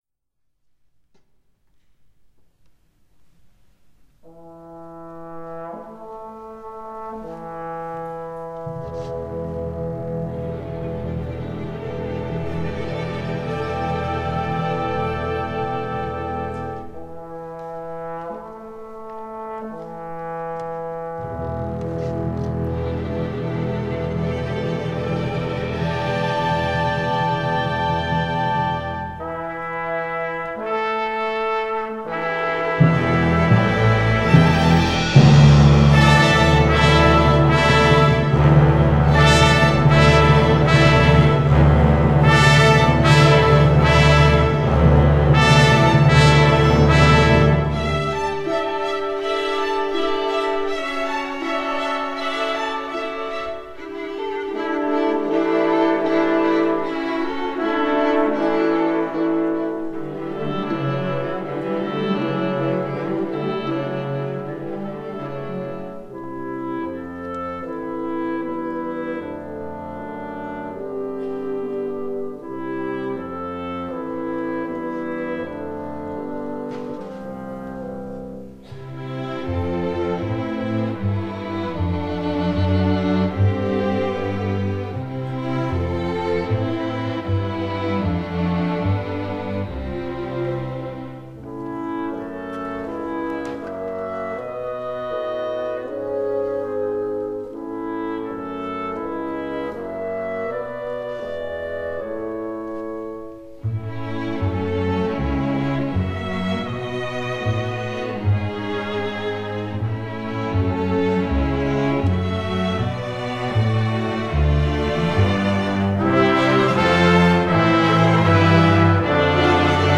Ensemble: Full Orchestra , Chamber Orchestra